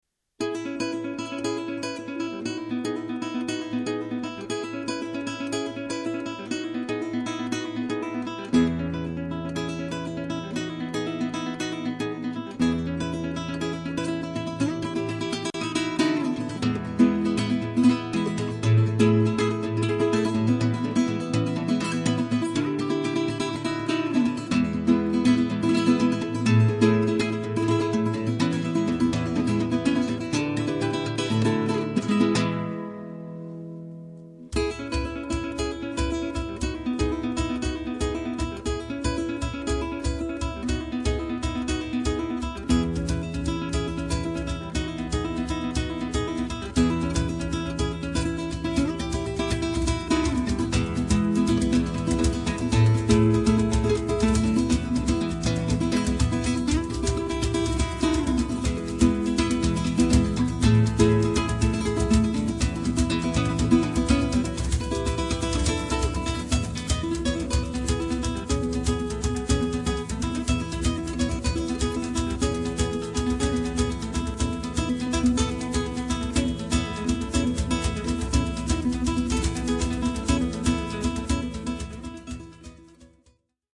chitarra
percussioni